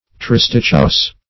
Search Result for " tristichous" : The Collaborative International Dictionary of English v.0.48: Tristichous \Tris"tich*ous\, a. [Gr.